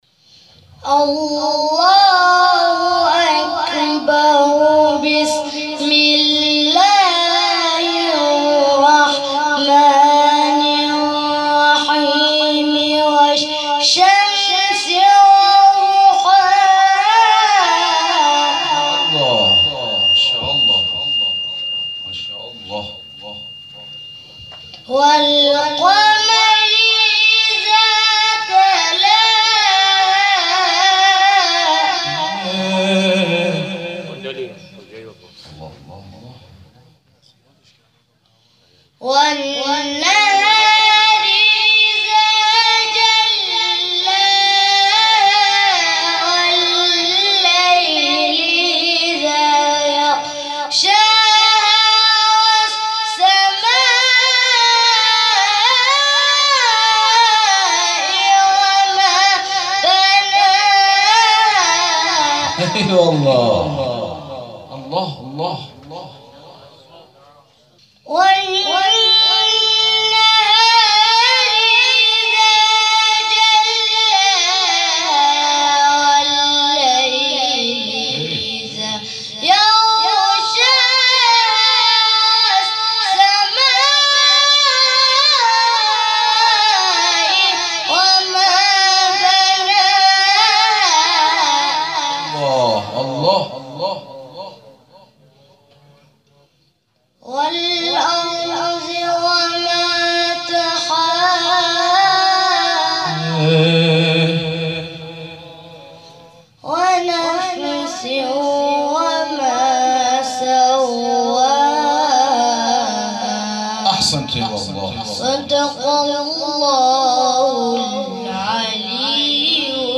در ادامه تلاوت های شب گذشته این جلسه قرآن و همچنین ابتهال حب الحسین(ع